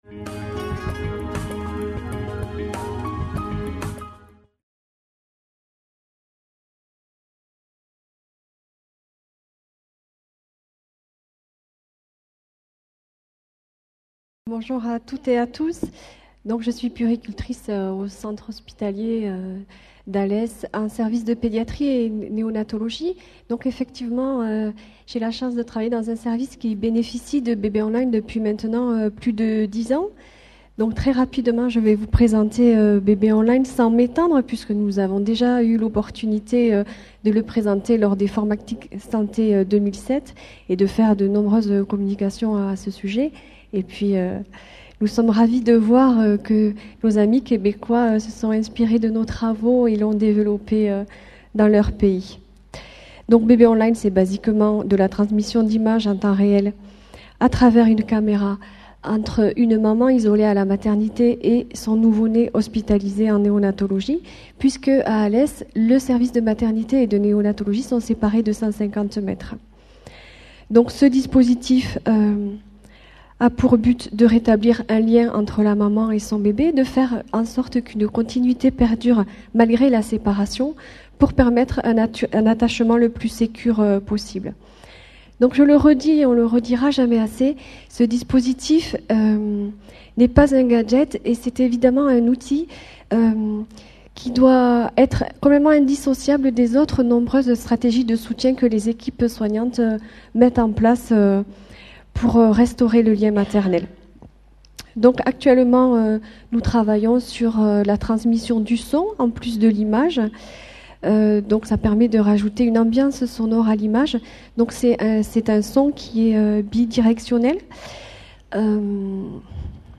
Dans quelles mesures une transmission d’images peut aider la maman à initialiser efficacement l’extraction de lait, principal moteur de la lactation ?Une maman séparée de son bébé à la naissance et désireuse d’allaiter exprime plus fréquemment son lait si elle bénéficie de bébé on line. Conférence enregistrée lors du congrès international FORMATIC PARIS 2011.